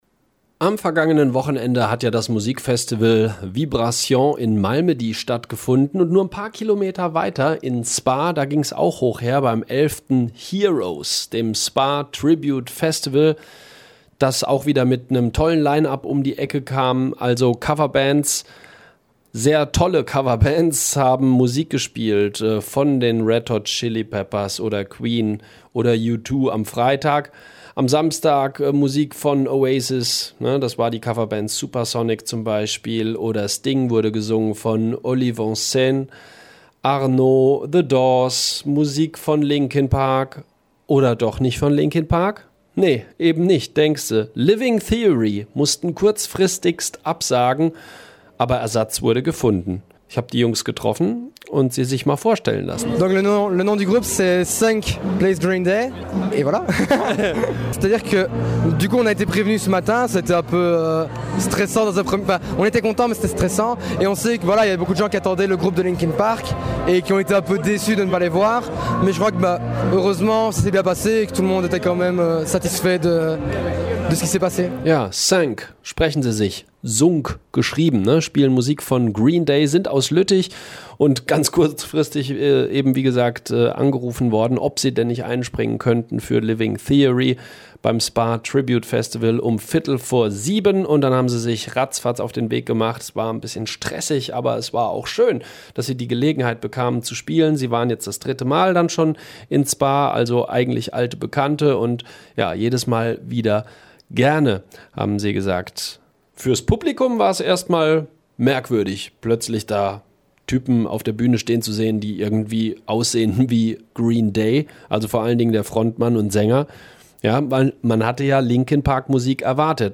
Am vergangenen Wochenende wurde in Spa wieder gecovert was das Zeug hielt, beim Spa Tribute Festival!